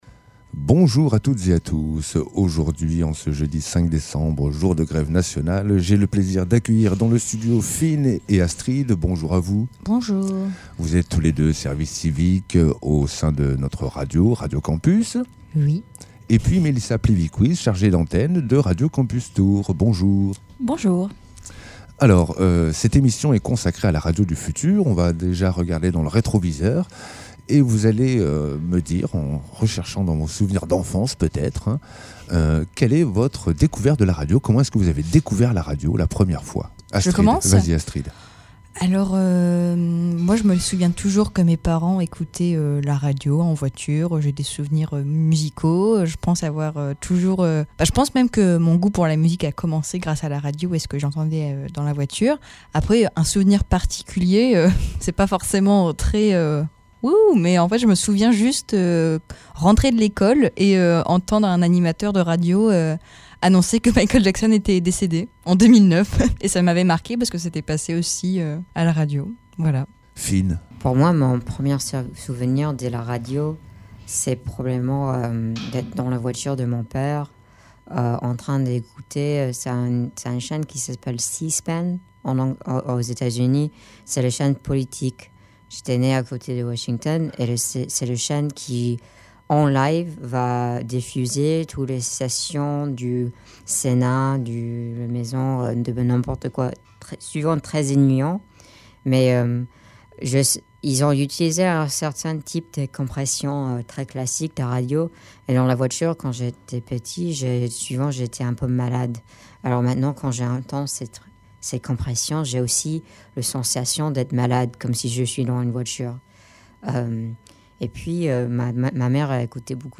Radio Campus Orléans fête ses 25 ans, et à cette occasion, La Méridienne s’invite à Orlinz !
debatradioturfu.mp3